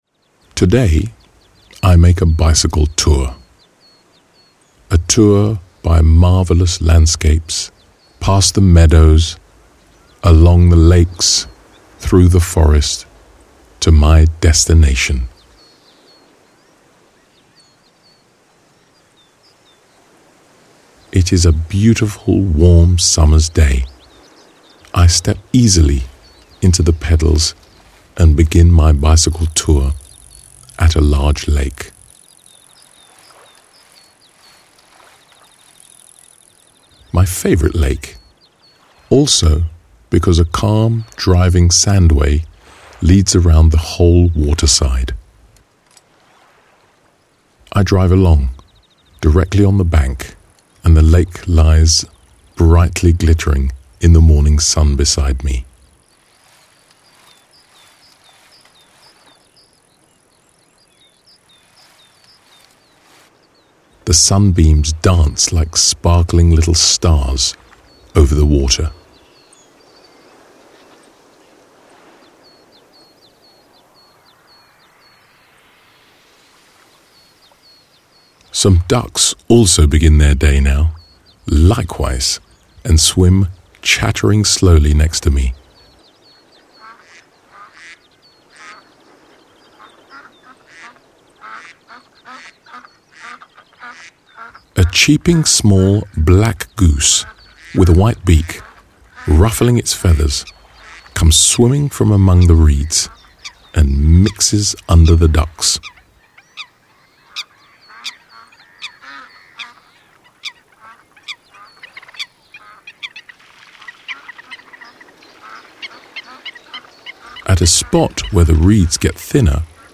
Buy audiobook:
The calm, clear voice and the spatial, gentle sounds will help you to quickly immerse yourself in a state of deep relaxation.